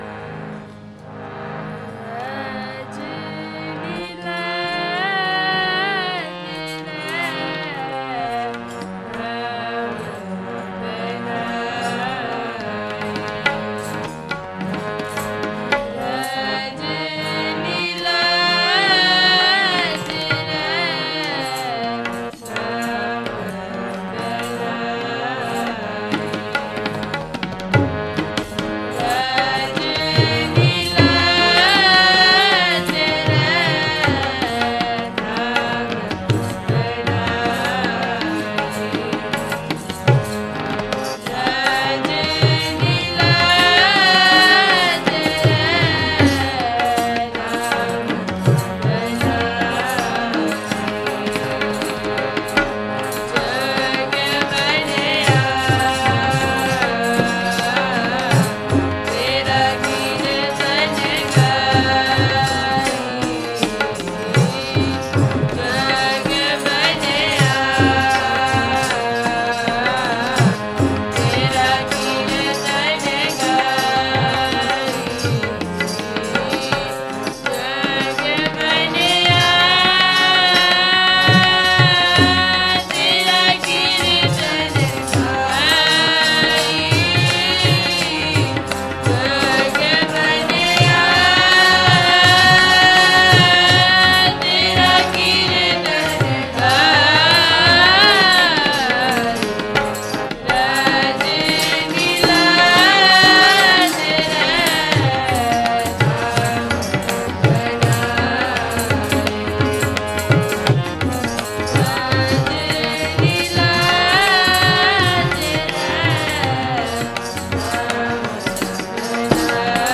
audio / Keertan /